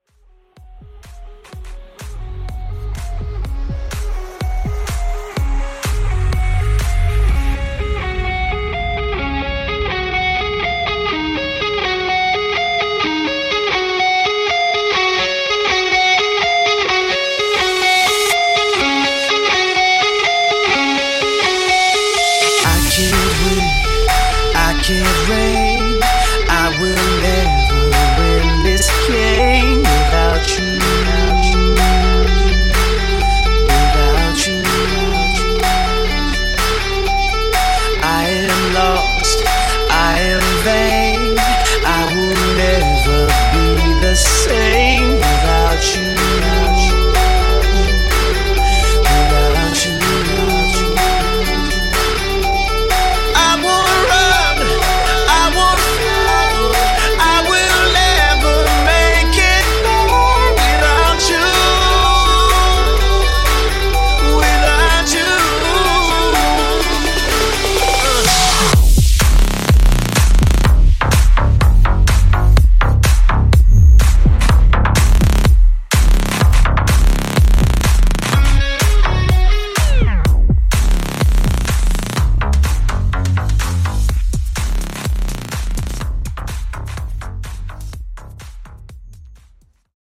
Mashup Bootleg Reboot)Date Added